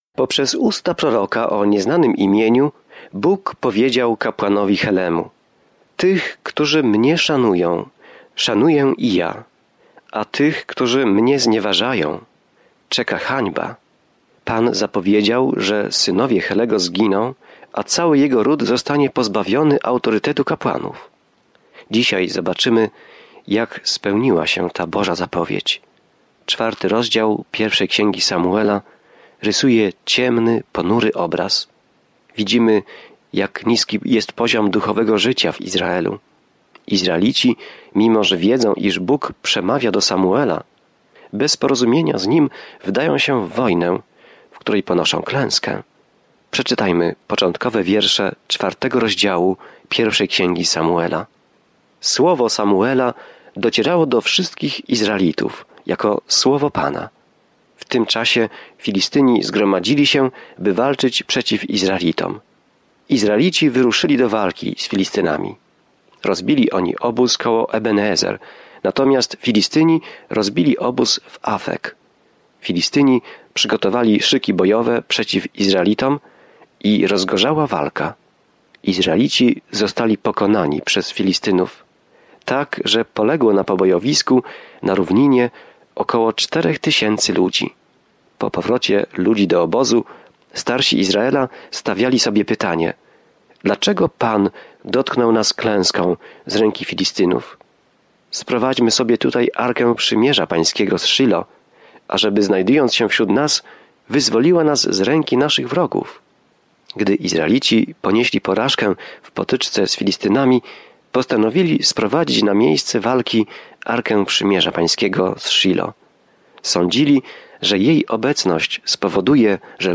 Pismo Święte 1 Samuela 4 1 Samuela 5 Dzień 3 Rozpocznij ten plan Dzień 5 O tym planie Najpierw Samuel zaczyna od Boga jako króla Izraela i kontynuuje historię o tym, jak Saul, a następnie Dawid, został królem. Codziennie podróżuj przez 1. Samuela, słuchając studium audio i czytając wybrane wersety ze słowa Bożego.